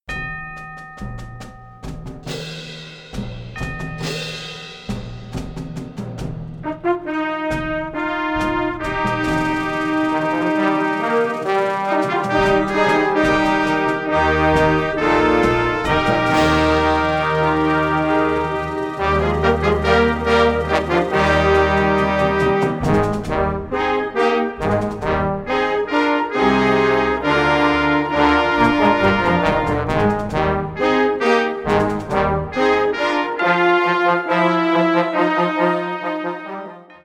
Sous-catégorie Musique de concert
Instrumentation Ha (orchestre d'harmonie)